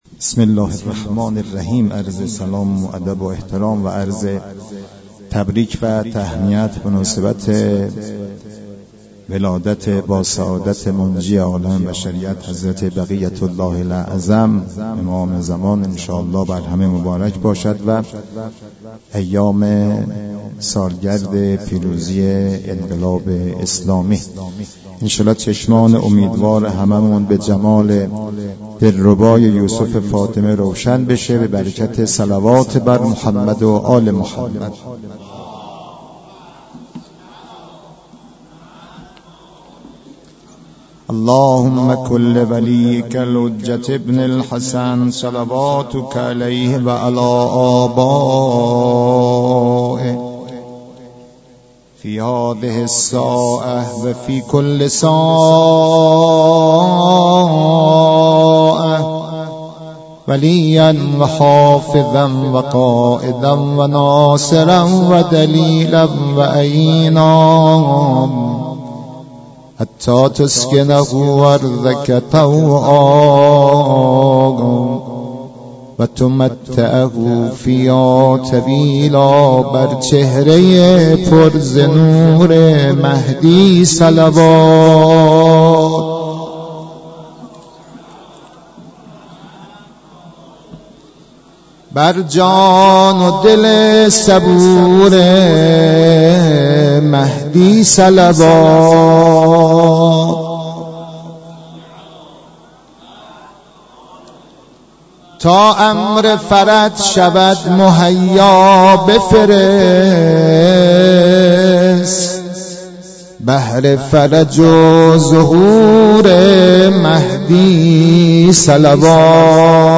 مدیحه سرایی
در مسجد دانشگاه کاشان ،به مناسبت ولادت با سعادتحضرت مهدی (عج)،